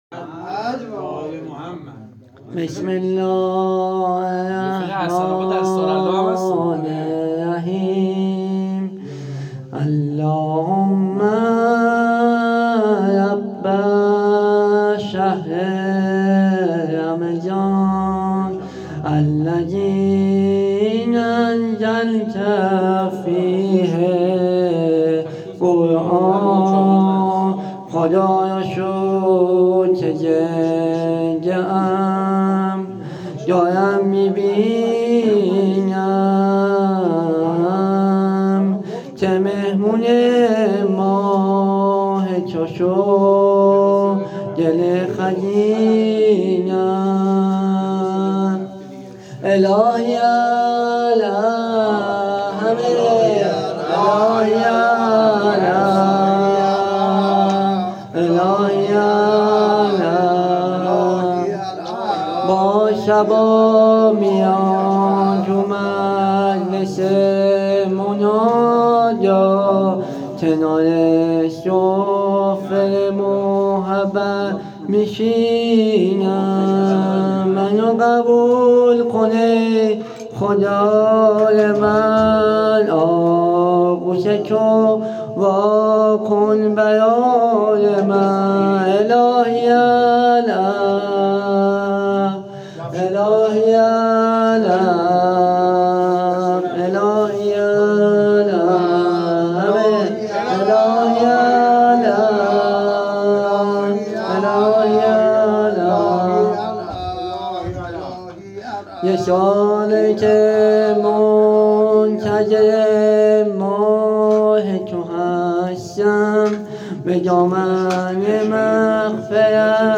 مسجد حاج حسن خرقانی تهران